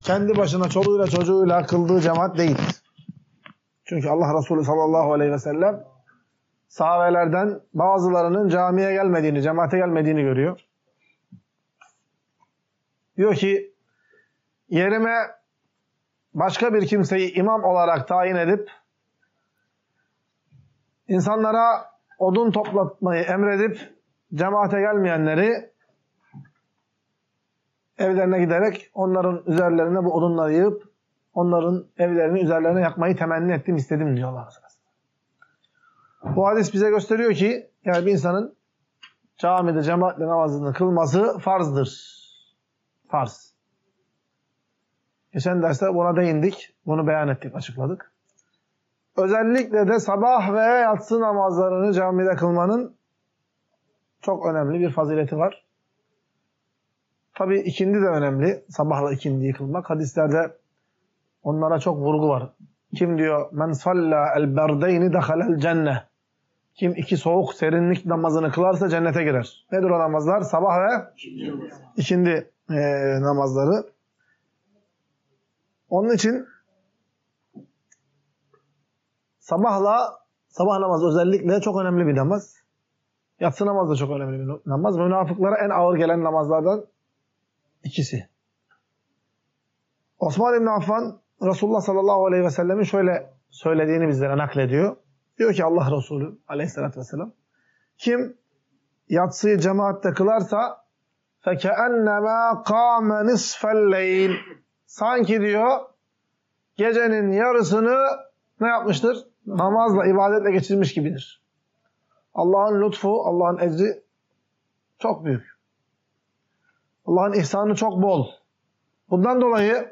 Ders - 13.